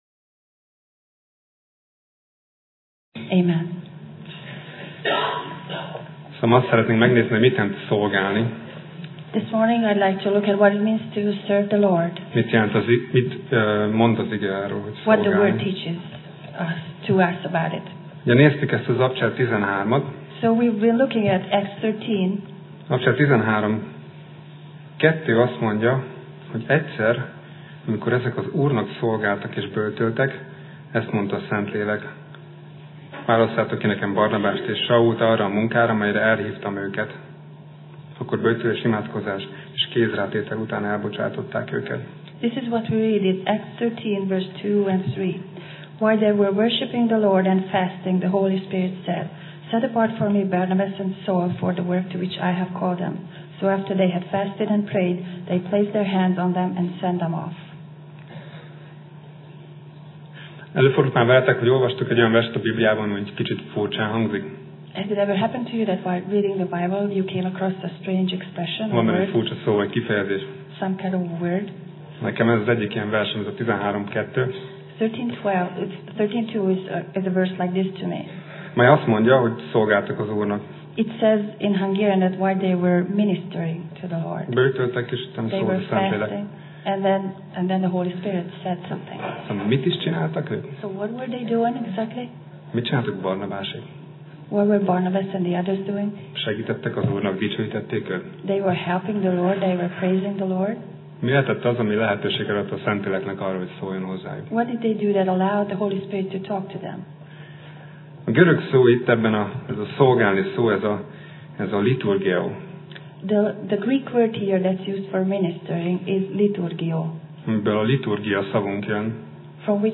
Tematikus tanítás Passage: Apcsel (Acts) 13:1 Alkalom: Vasárnap Reggel